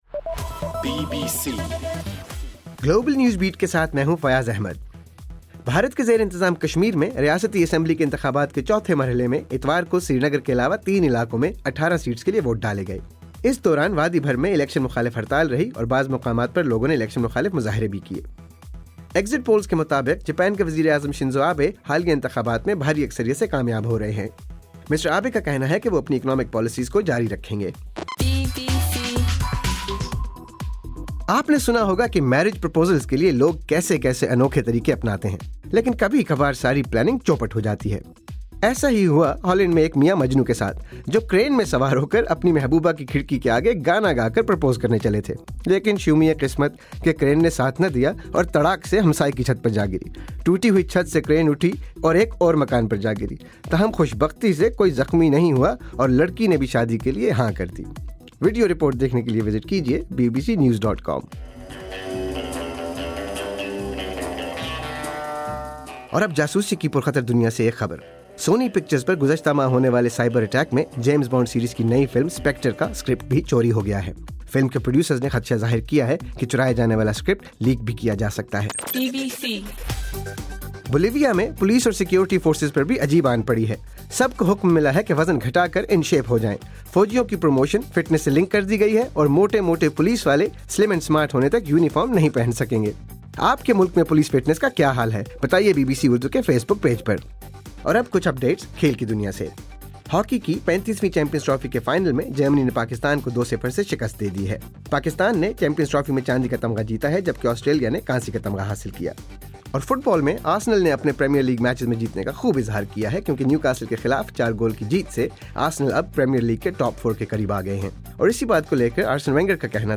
دسمبر 15: صبح 1 بجے کا گلوبل نیوز بیٹ بُلیٹن